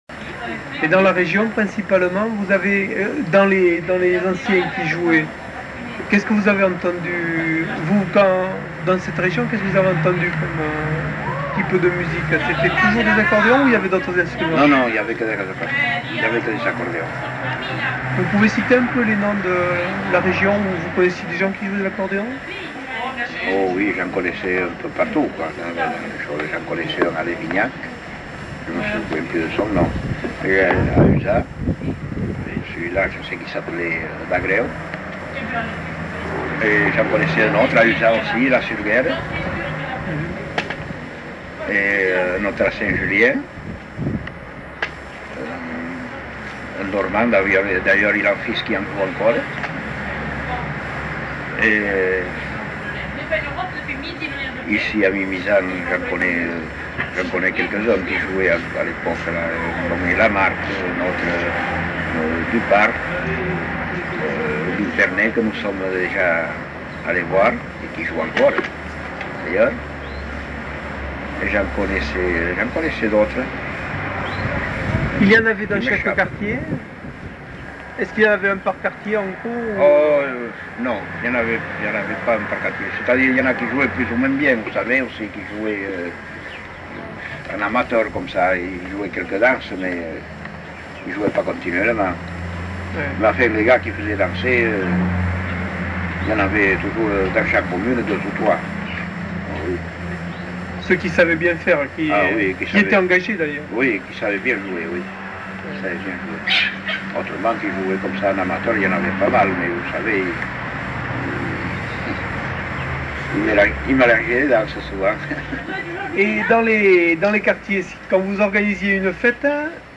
Lieu : Mimizan
Genre : témoignage thématique